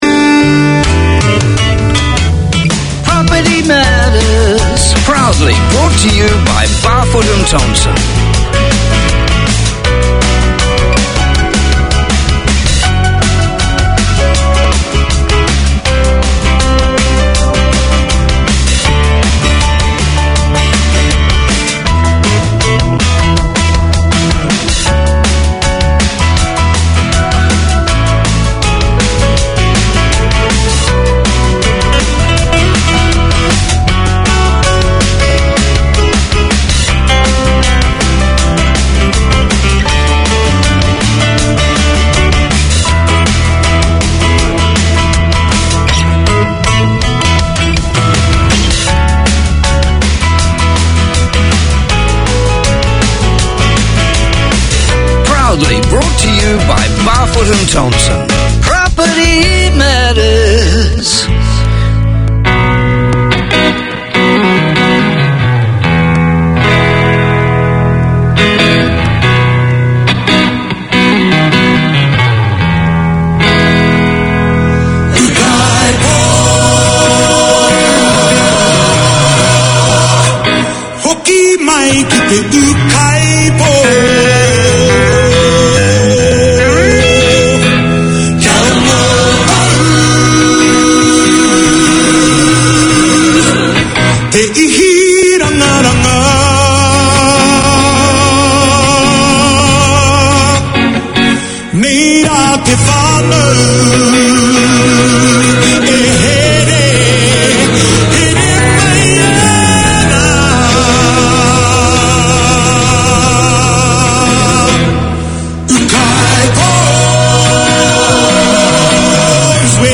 Garden Planet tackles everything from seasonal gardening and garden maintenance, to problem-solving, troubleshooting, and what to plant and when. Tune in for garden goss, community notices and interviews with experts and enthusiasts on all things green or growing.